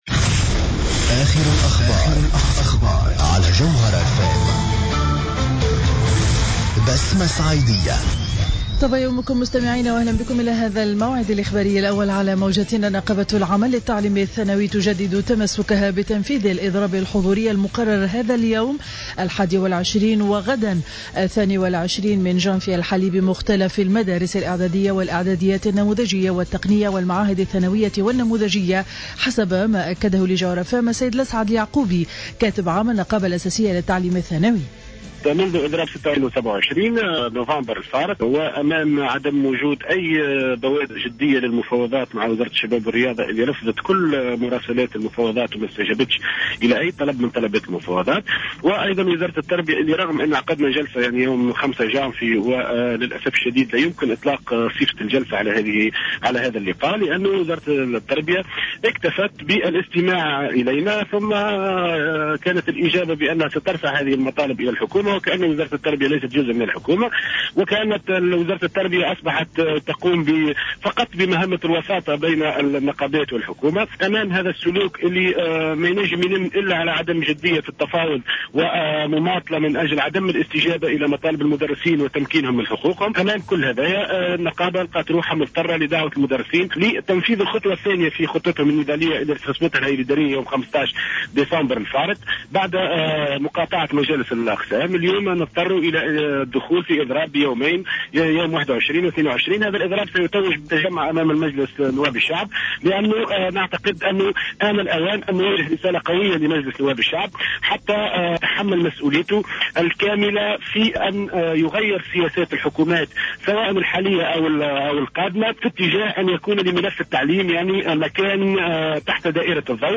نشرة أخبار السابعة صباحا ليوم الإربعاء 21 جانفي 2014